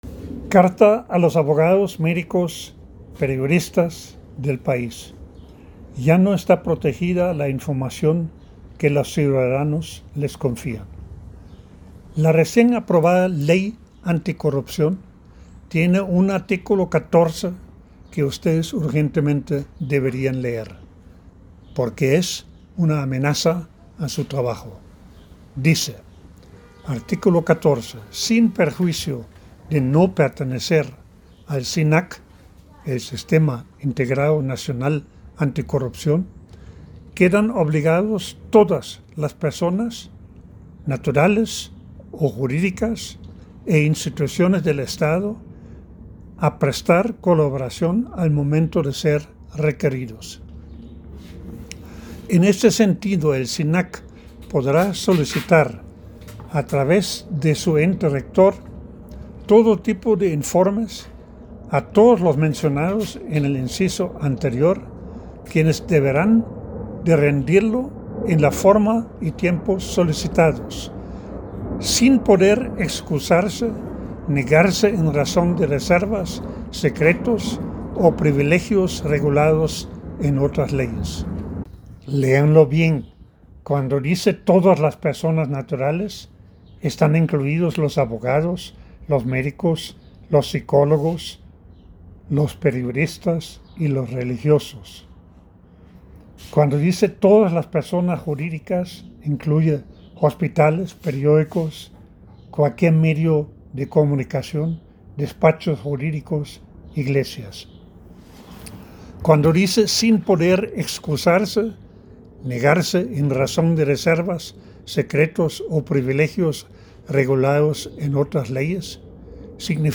En la voz del autor: